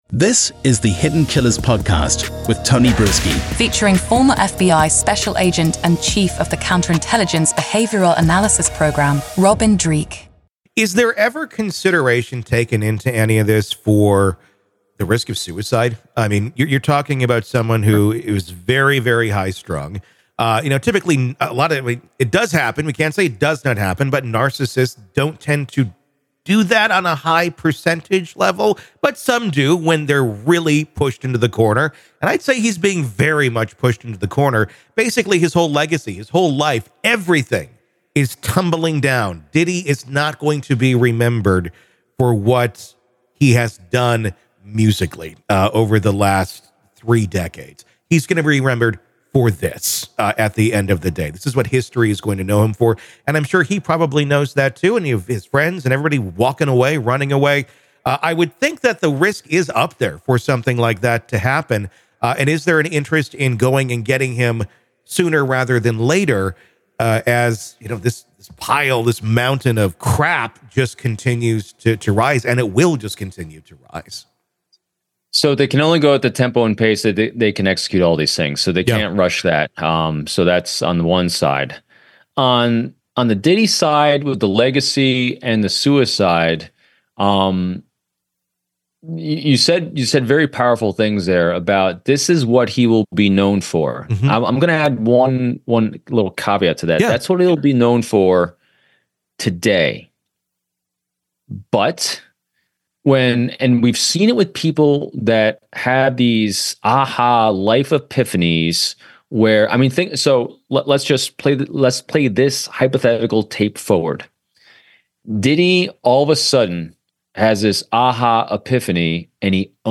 This transformation, though unlikely, could potentially shift public perception and inspire others. The conversation touches on the authenticity of such a shift, the pain involved in genuine redemption, and the inspirational impact it could have.